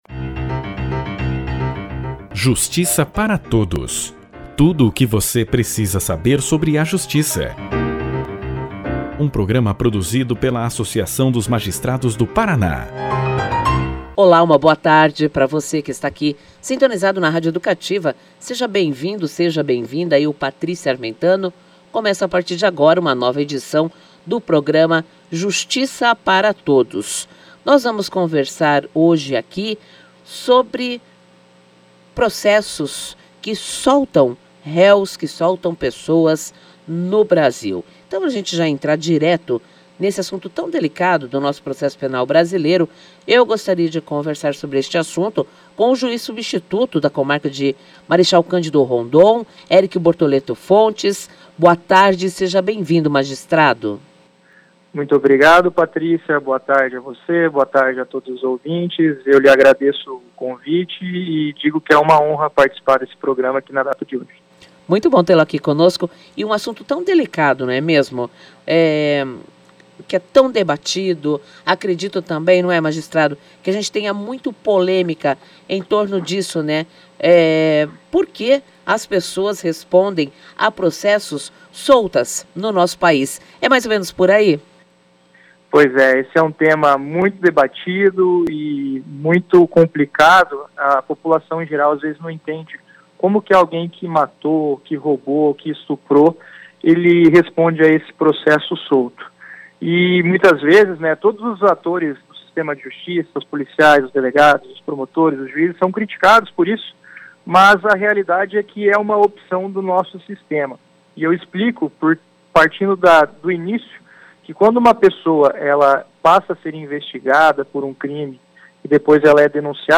O Juiz Substituto da Comarca de Marechal Cândido Rondon, Eric Bortoletto Fontes, participou do programa de rádio da AMAPAR, Justiça para Todos, e explicou aos ouvintes sobre os casos em que as pessoas respondem processos em liberdade.